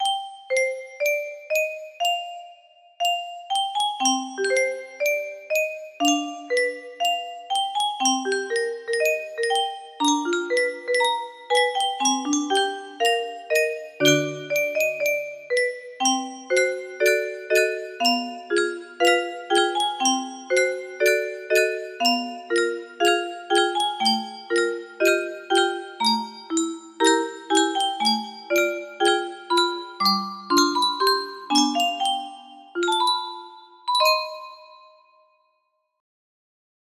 build up to complex chords